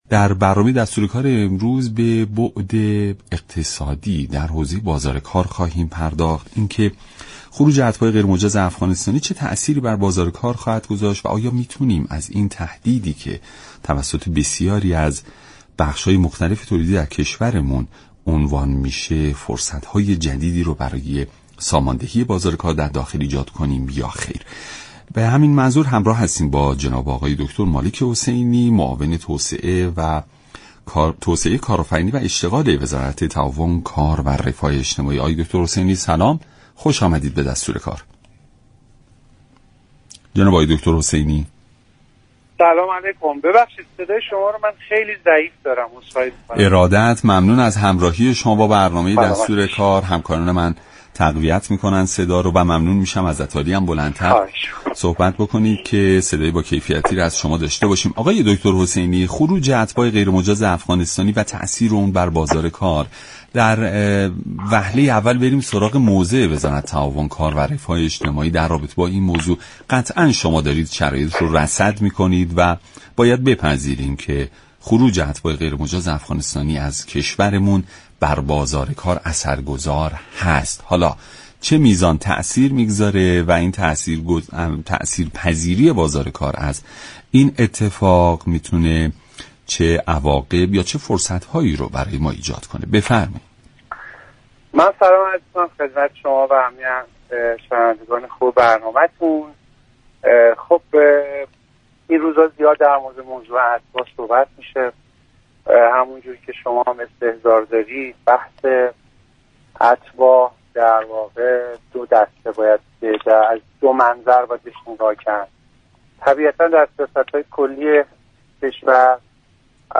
معاون توسعه كارآفرینی و اشتغال وزارت تعاون، كار و رفاه اجتماعی در برنامه دستوركار گفت: هر چند خروج اتباع غیرقانونی از ایران بحرانی را در بازار ایجاد نمی‌كند اما بخشی از حوزه كشاورزی برداشت محصول در برخی از مناطق را تحت‌تاثیر خود قرار می‌دهد.